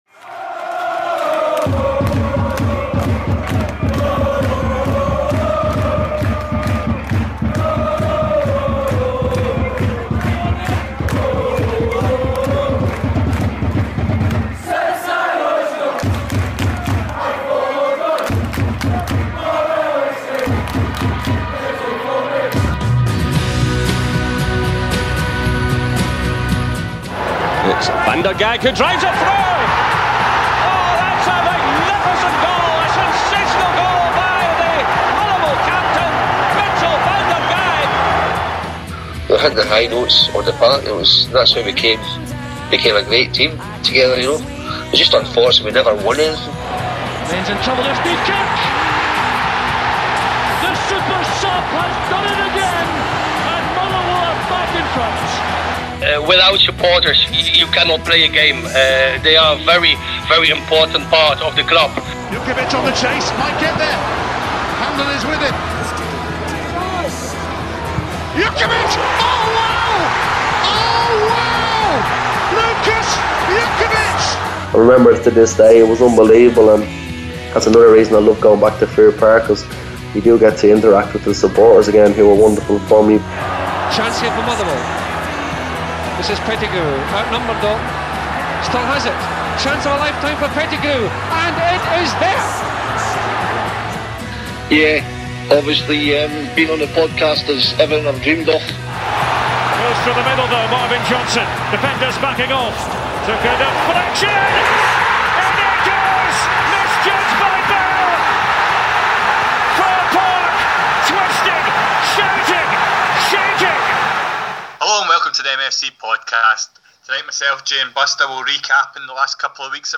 Due to sound problems with the original recording, a back-up was used so apologies for any audible background noise!